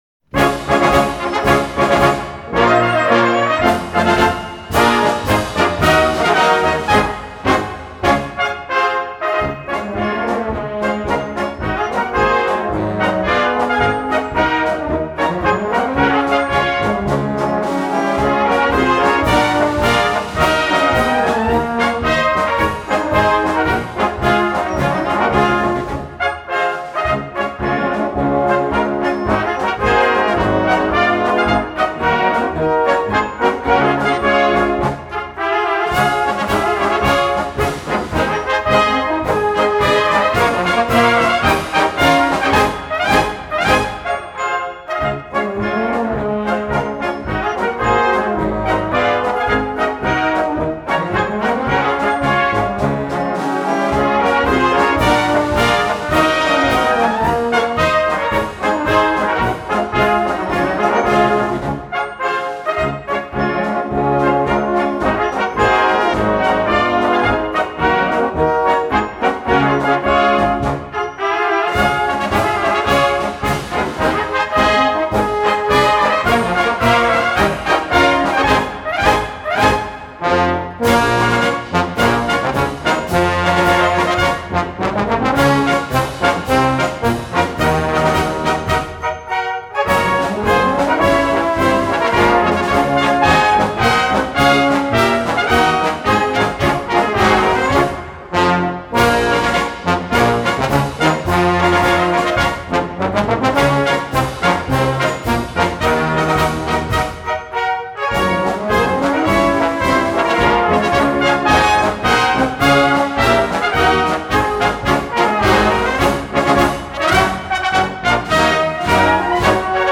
Gattung: Konzertmarsch für Blasorchester
Besetzung: Blasorchester